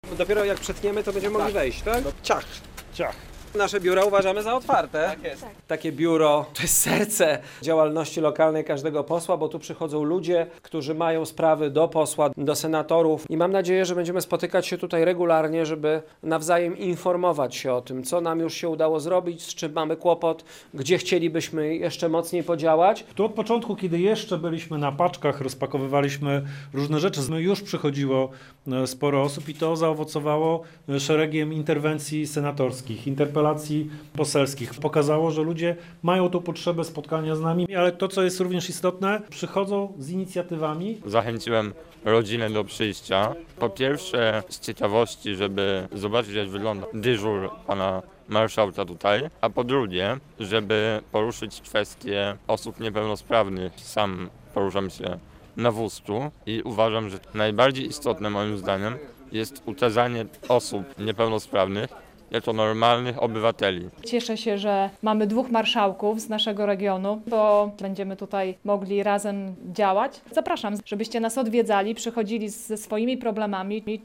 Szymon Hołownia, Maciej Żywno i Barbara Okuła oficjalnie otworzyli swoje biura w Białymstoku - relacja